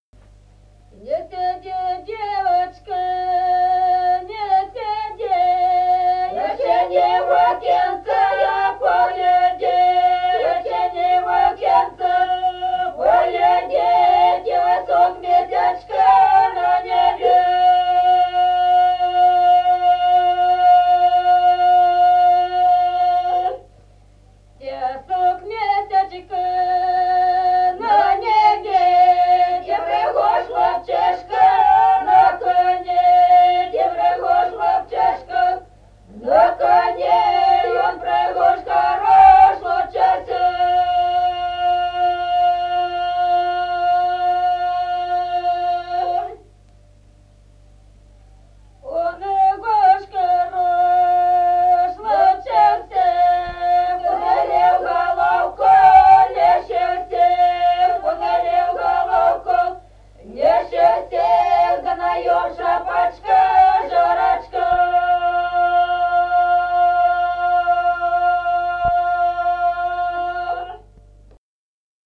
Песни села Остроглядово. Не сиди, девочка.